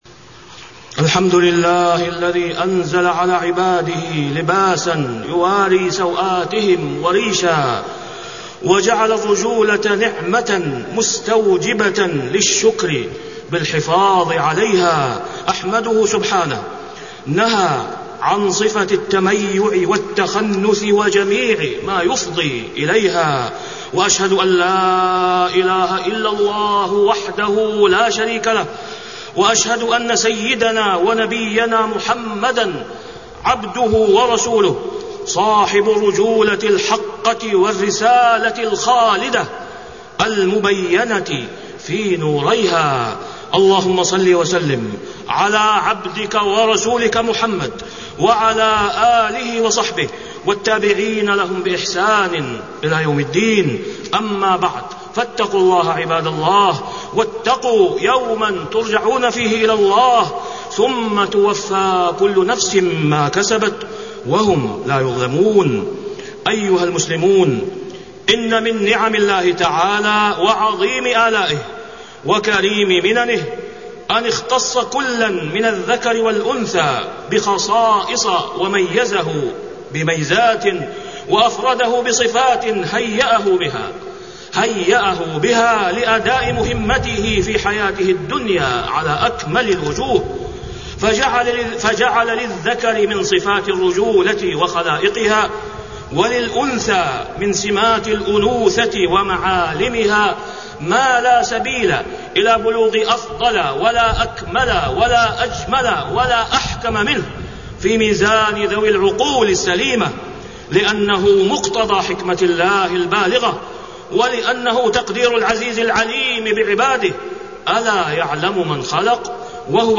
تاريخ النشر ٢٩ رجب ١٤٢٩ هـ المكان: المسجد الحرام الشيخ: فضيلة الشيخ د. أسامة بن عبدالله خياط فضيلة الشيخ د. أسامة بن عبدالله خياط لأزياء المخالفة لشرع الله